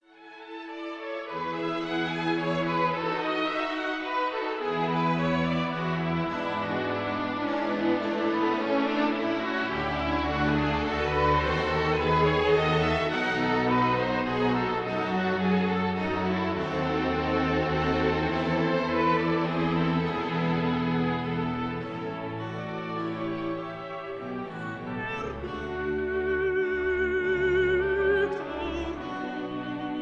Contralto
Organ